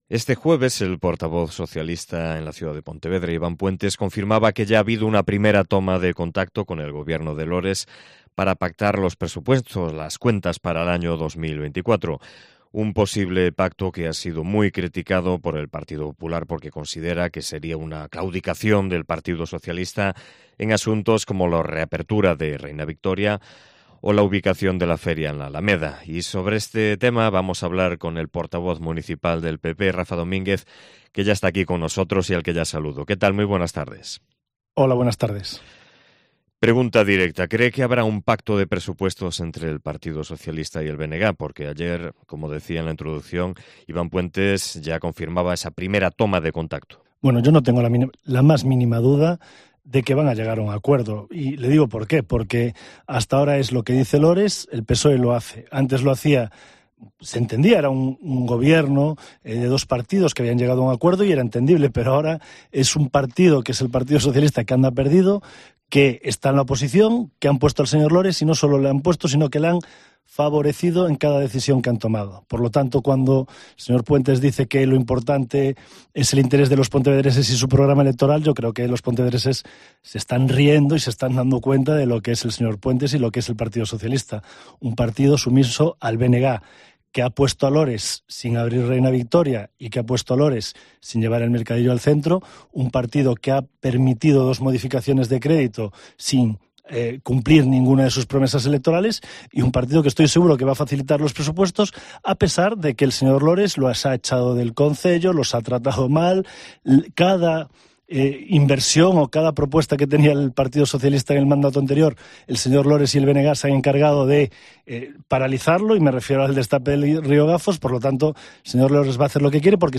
Entrevista con Rafa Domínguez, líder del PP de Pontevedra
AUDIO: Entrevista patrocinada por el Grupo Municipal del Partido Popular